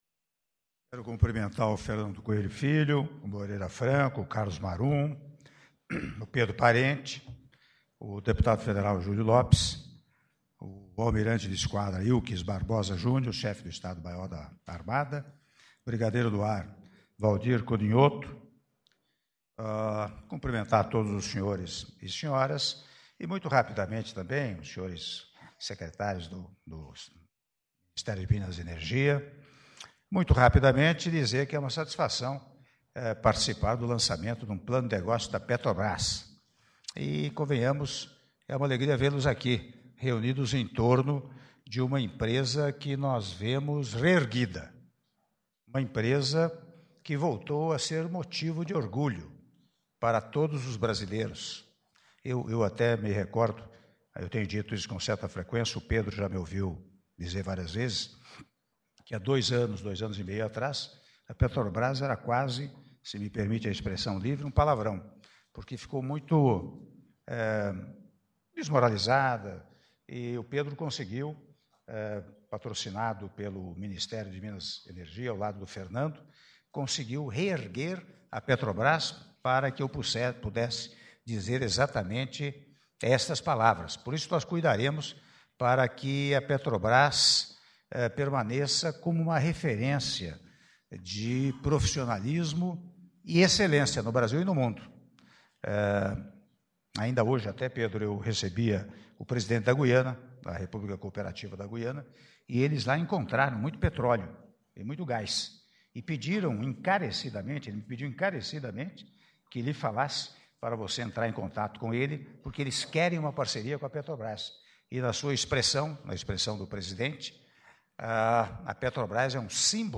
Áudio do Discurso do Presidente da República, Michel Temer, durante cerimônia de divulgação do Plano de Negócios e Gestão 2018-2022 da Petrobras - Palácio do Planalto (07min02s)